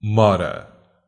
Ääntäminen
IPA : /dɪˈleɪ/